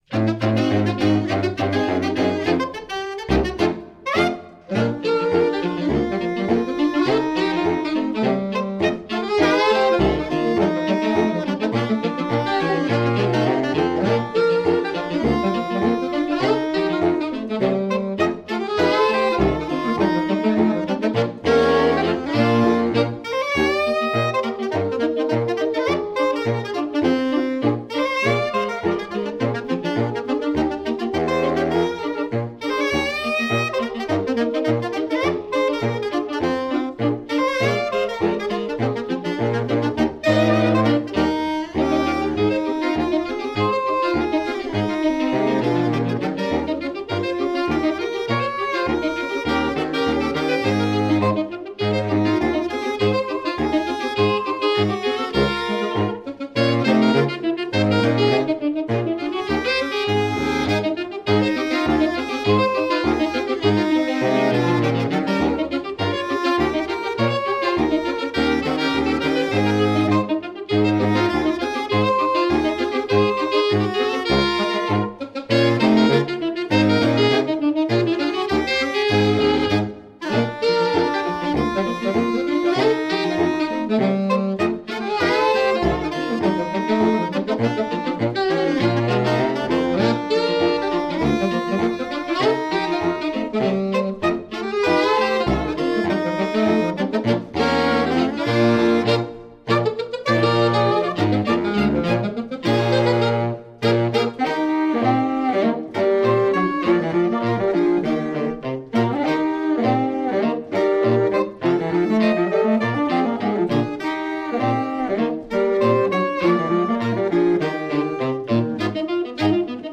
Performing in various authentic 1920s wardrobes, we’re sure you’ll enjoy the vibrant and beautiful music from the South’s premier sax quartet.
Basement Sax bring their own style, passion and diverse experiences to form a professional saxophone quartet suitable for any occasion.
The band specialises in vintage 1920s Jazz, focusing on image, authenticity, and a high standard of music - the ideal alternative to a traditional string quartet.